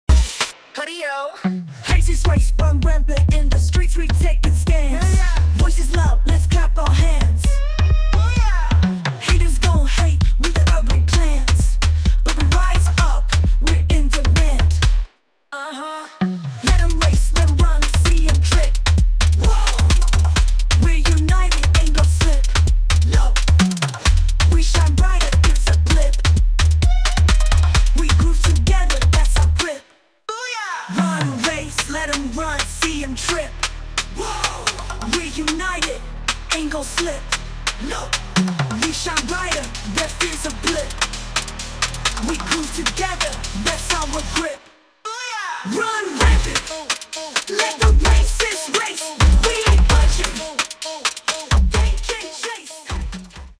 An incredible Hip Hop song, creative and inspiring.